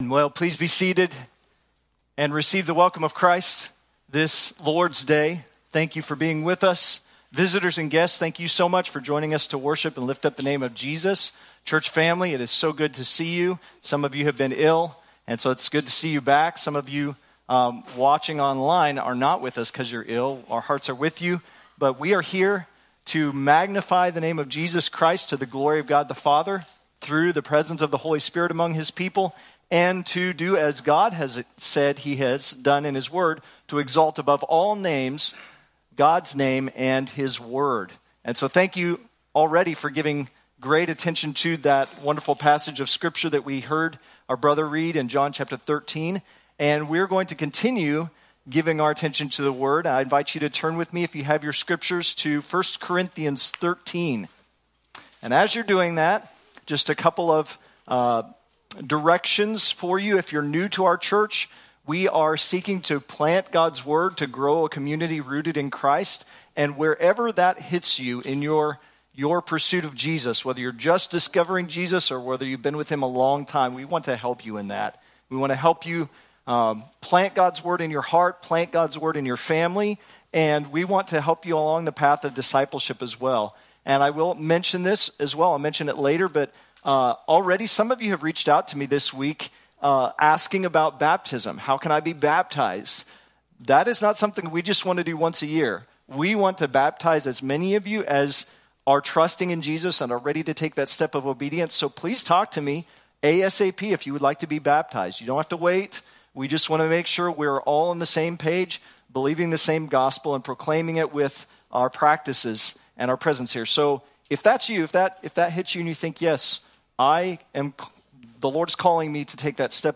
Sermons | Redemption Bible Church